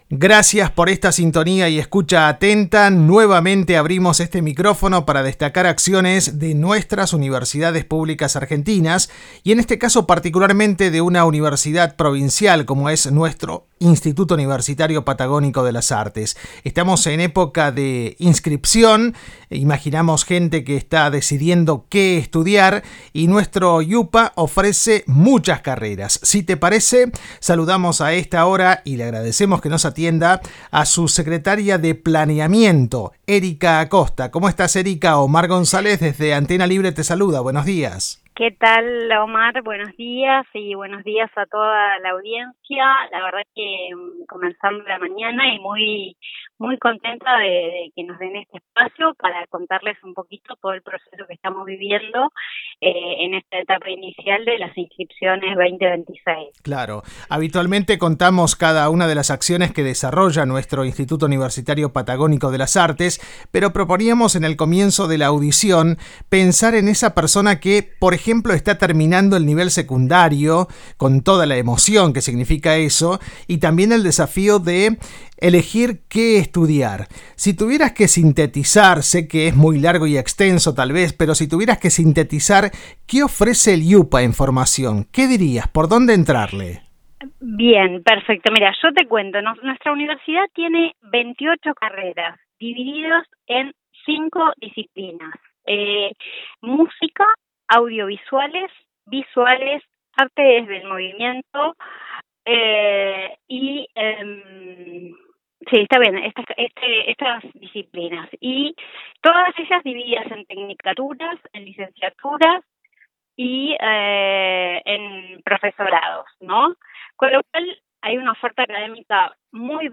En diálogo con Horizonte Universitario